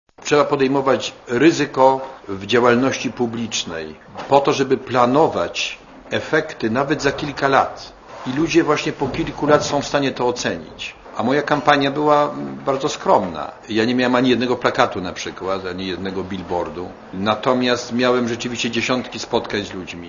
* Mówi Jerzy Buzetk*
"Otrzymane poparcie to dla mnie dowód, że ludzie w gruncie rzeczy są w stanie docenić, jeśli ktoś ponosi pewne ryzyko polityczne podjętych działań, jeśli to ryzyko ma przynieść dobro wspólne i nie ma żadnych innych podtekstów w tym, co się robi" - powiedział Buzek podczas wtorkowej konferencji prasowej w Katowicach.